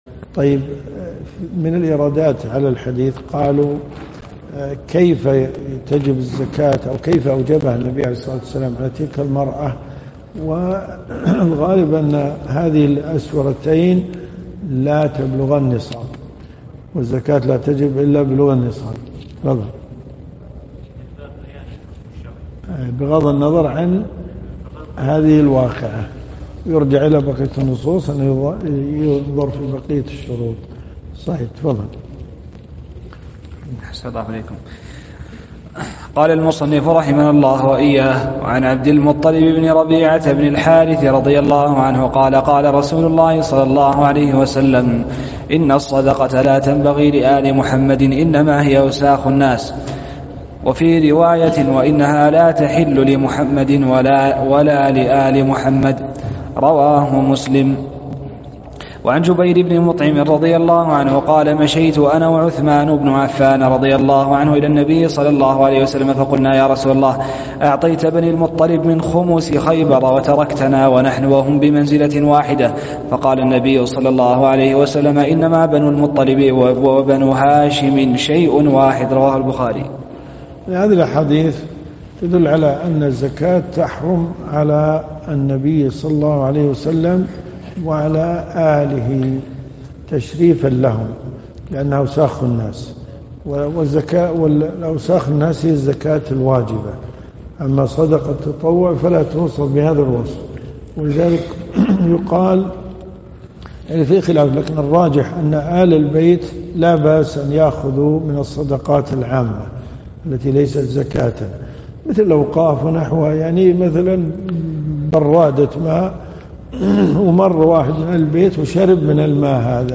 الرئيسية الدورات الشرعية اليوم العلمي [ قسم أحاديث في الفقه ] > بلوغ المرام . الرياض . حي العارض . جامع عبدالله بن ناصر المهيني . 1445 + 1446 .